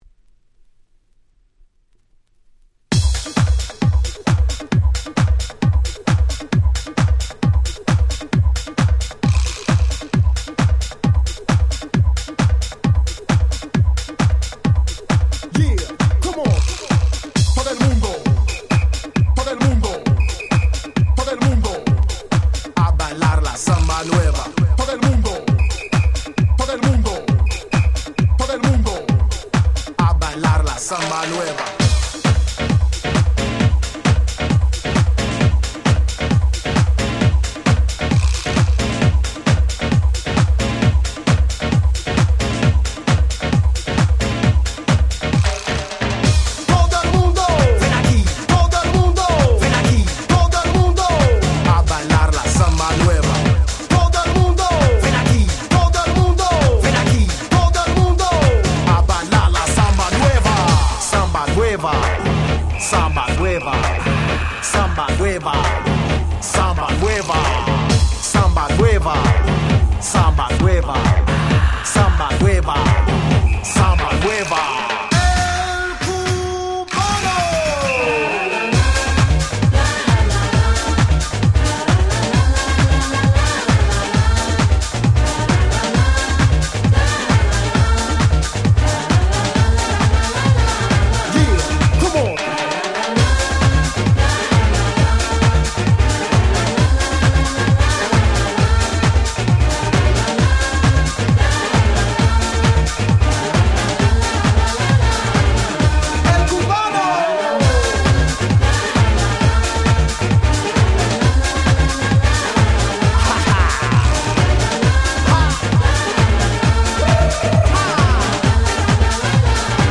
98' Super Nice Latin House !!
アッパーで最高なラテンハウス！！！
サンバでカーニバルなアゲアゲの1曲！！
90's ブラジリアンハウス